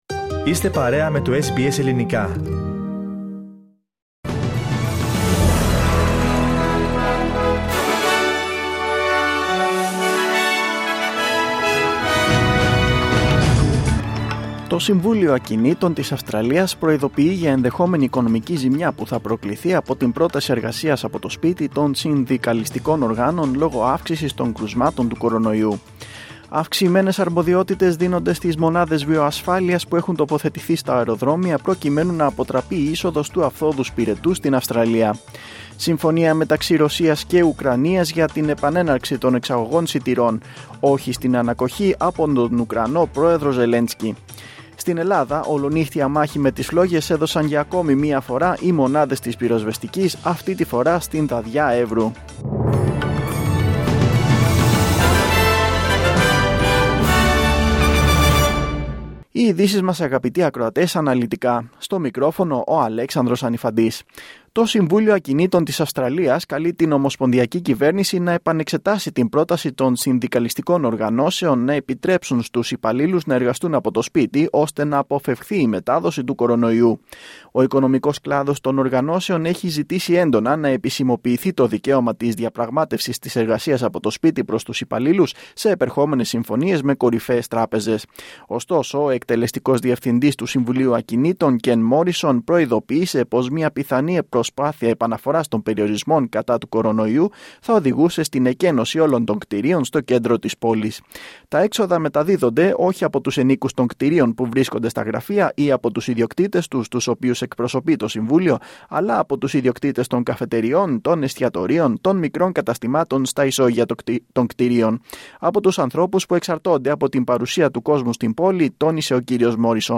Δελτίο Ειδήσεων Σάββατο 23.7.2022
News in Greek. Source: SBS Radio